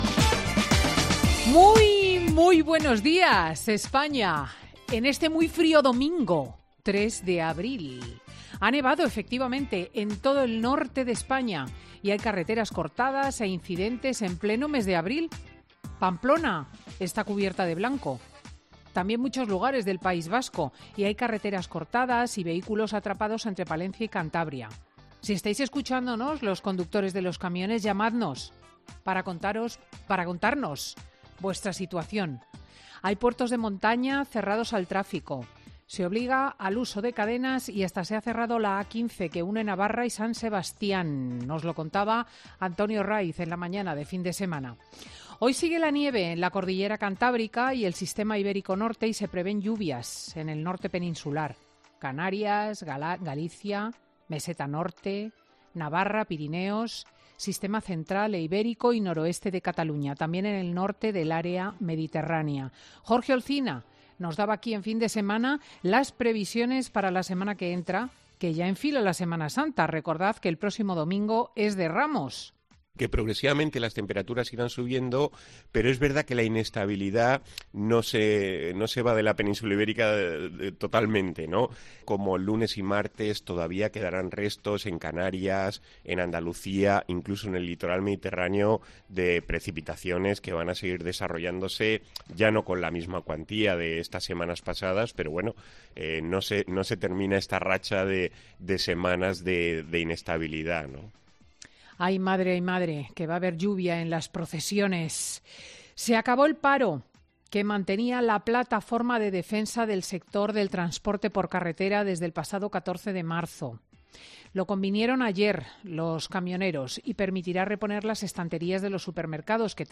El congreso del PP, el precio de la gasolina o la 'simbólica' derrota de Rusia, en el monólogo de Cristina López Schlichting de este domingo